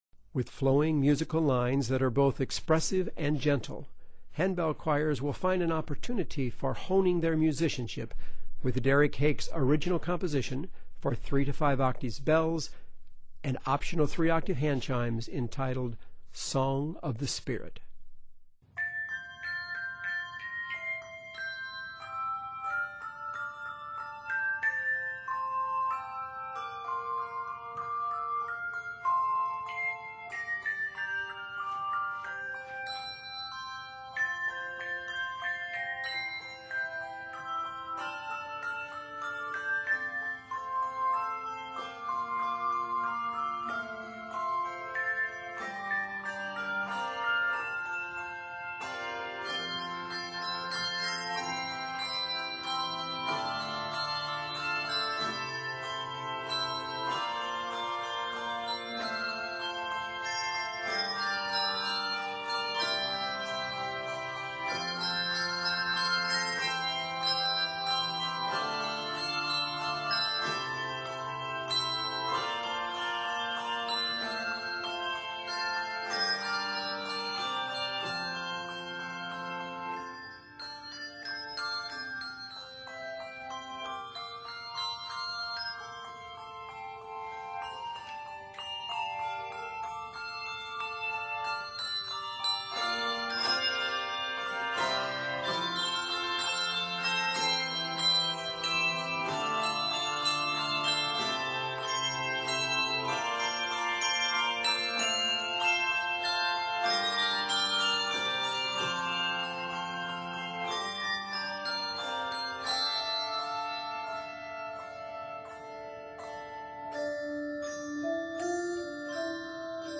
handbells and choir chimes
scored in F Major and d minor